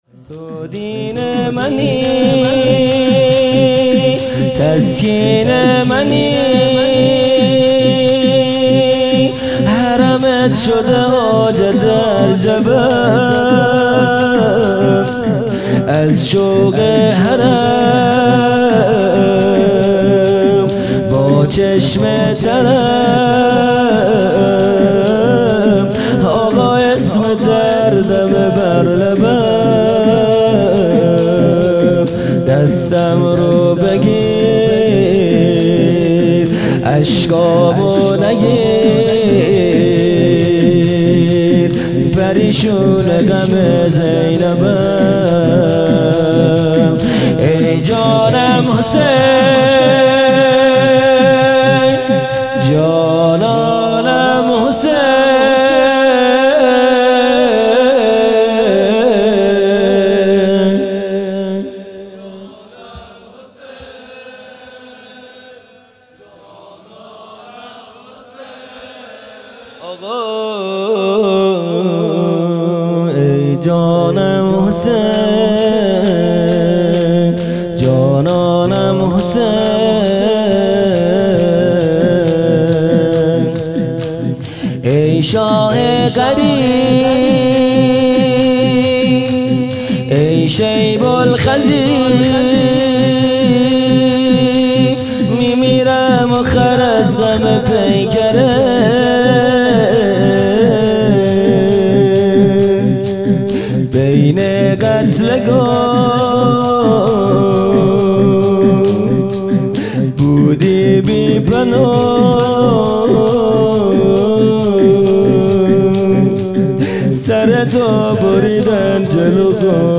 خیمه گاه - هیئت بچه های فاطمه (س) - شور | تو دین منی
جلسۀ هفتگی (به مناسبت شهادت حضرت حمزه(س))